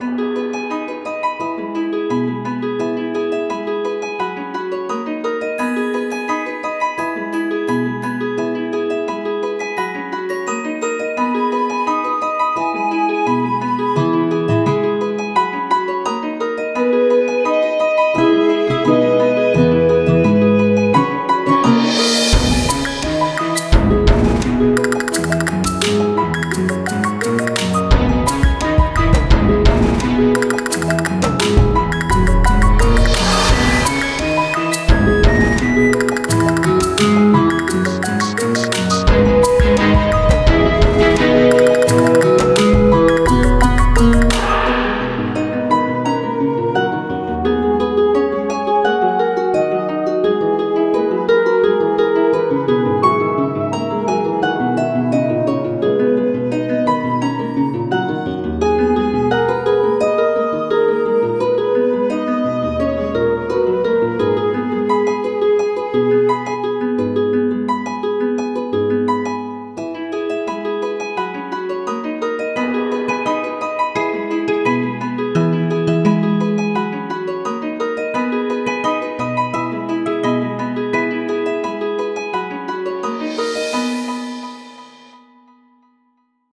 An adventerous overworld tune where the arpeggios remind me of falling snow. Could also make decent intro or ending music.